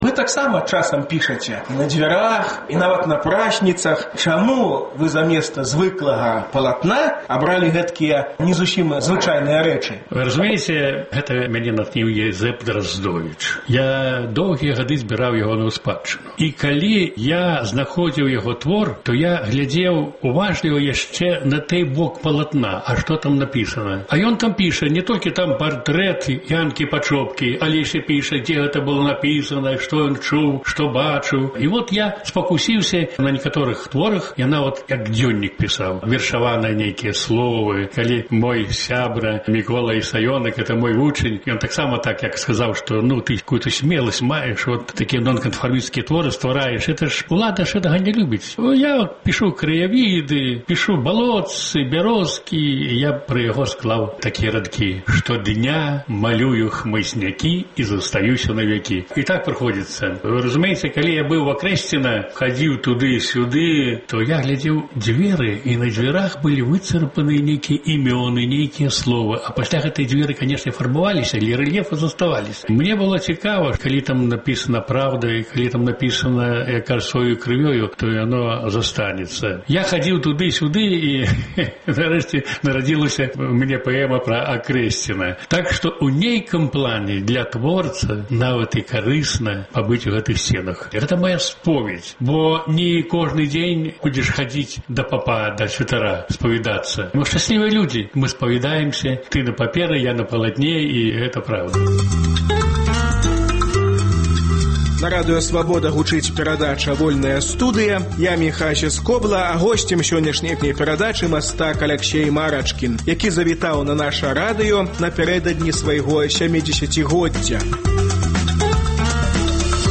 Мастак, якога натхняе дыктатура: гутарка з Аляксеем Марачкіным напярэдадні яго 70-годзьдзя.